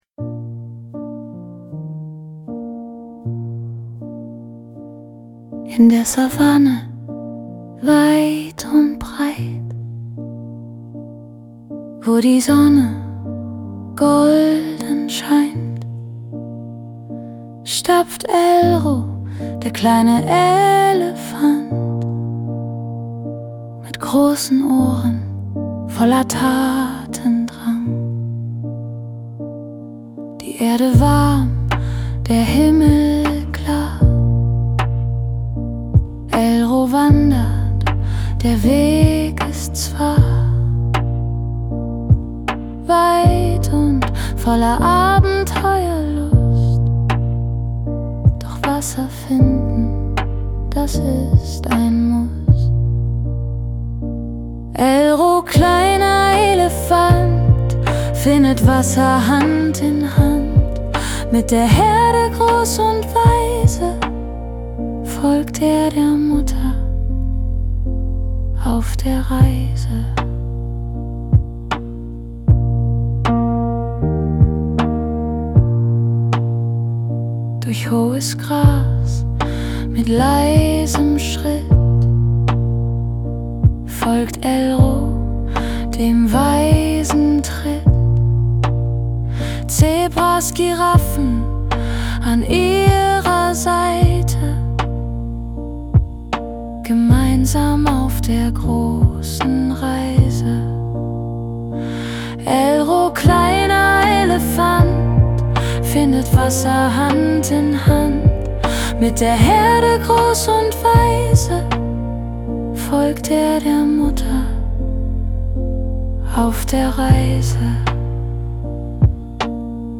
Das Schlaflied zur Geschichte
🎵 Musik und Gesang: Suno | AI Music